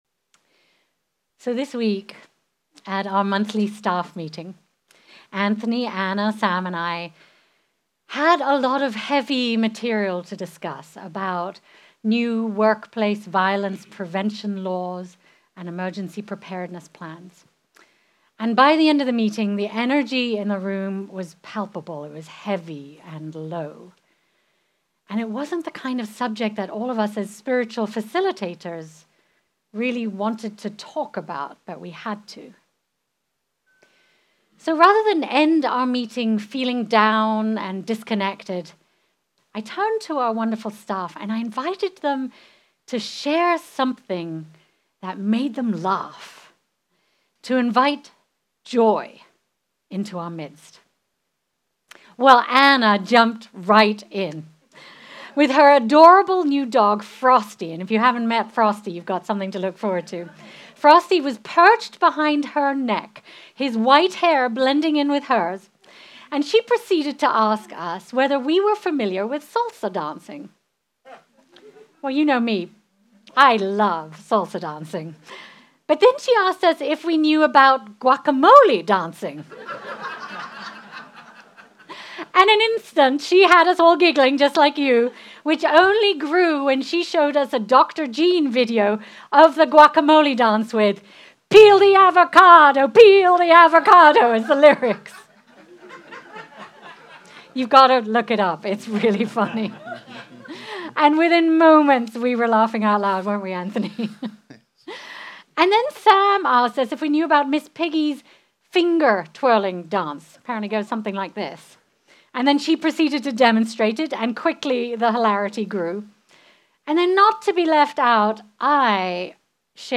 Our Ingathering is a special annual service inviting us all to return to the home of our souls, our precious Chalice community. Today we offer an invitation to joy, inviting you to experience joy in your lives, in our community, service and celebration.